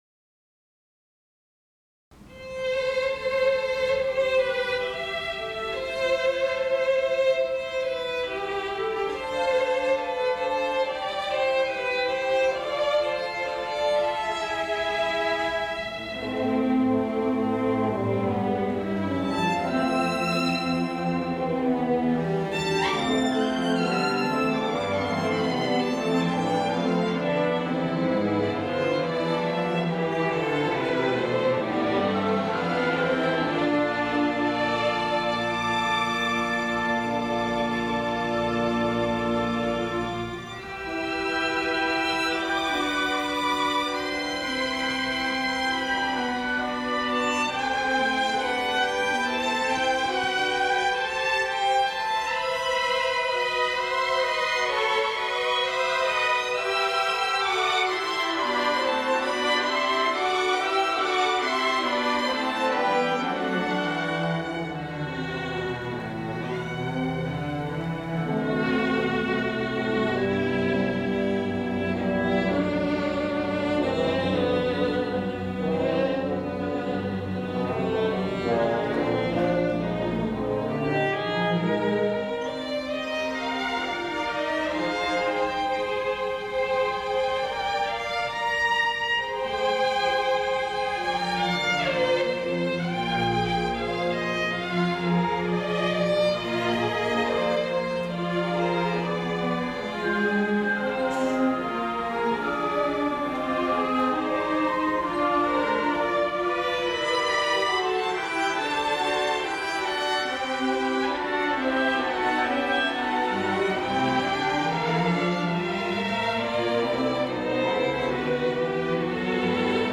for Violin and Chamber Orchestra
Premiere performance
violin
Adagio andalusia     [7:50]
This composition uses the Diatonic Phrygian Tetrachord both unmodified and highly modified in pretty much every way I could think of, frequently with several modifications simultaneously, in a reasonably contemporary idiom.